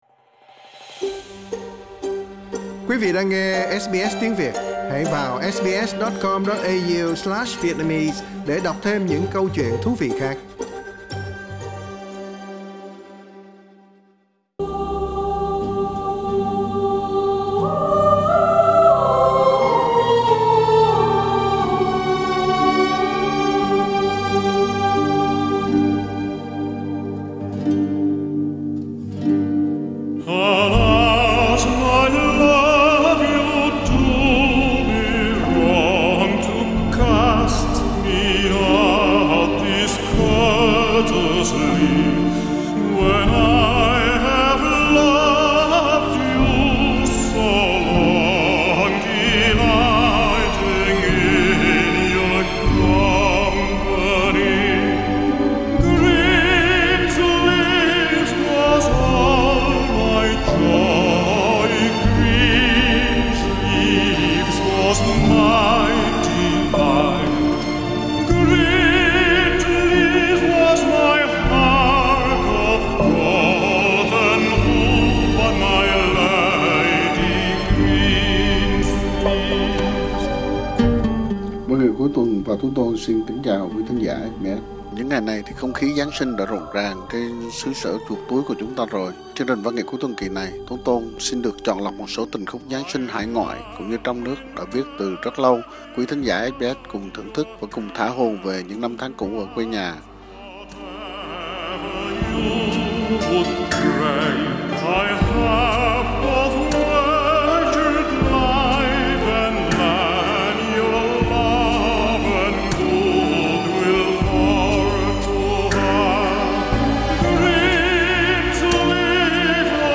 Tiếng ngân vang của những bài thánh ca bất hủ, những giai điệu của nhạc tình mùa Chúa Hài Đồng đưa ký ức quay về với những hoài niệm...